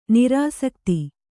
♪ nirāsakti